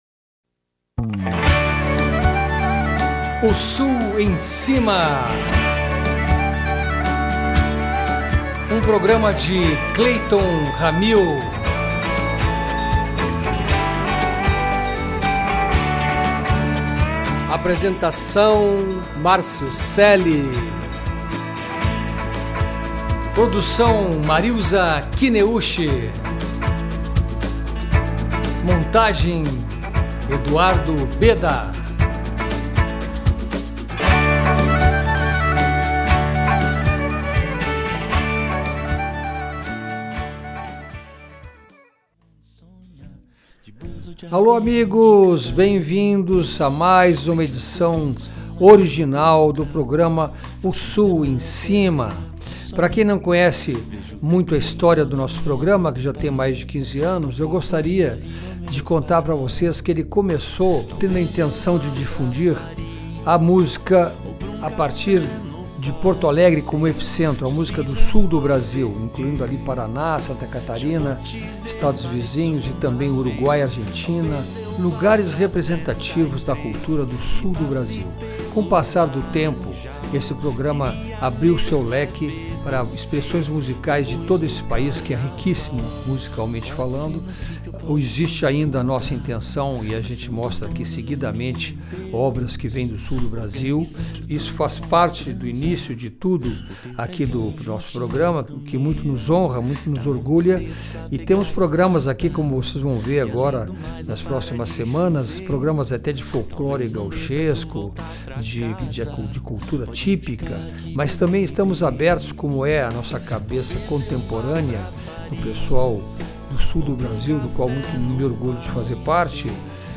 toca violão e é produtor musical.
Viola Caipira de dez cordas
indie pop, MPB e folk